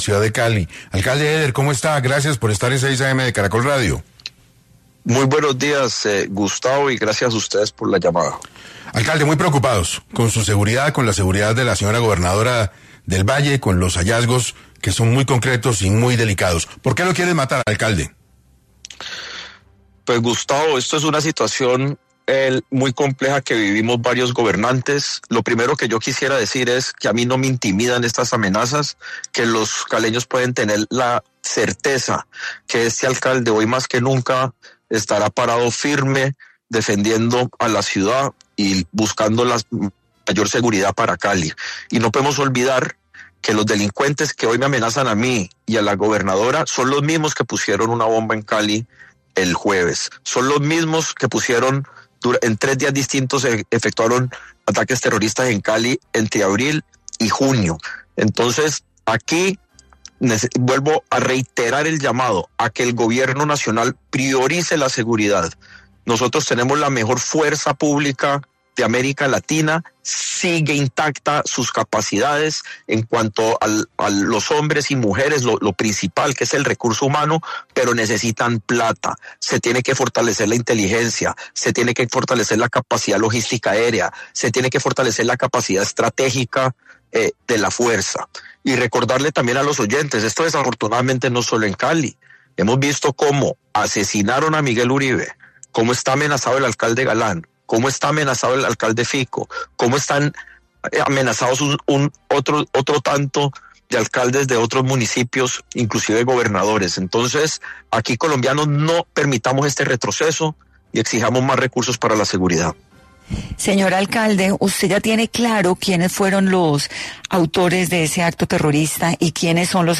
En entrevista con 6AM de Caracol Radio, Eder afirmó que no se dejará intimidar y que los responsables son los mismos que han perpetrado otros ataques en la ciudad, incluido el carro bomba en inmediaciones de la Escuela Militar de Aviación Marco Fidel Suárez, el pasado 21 de agosto.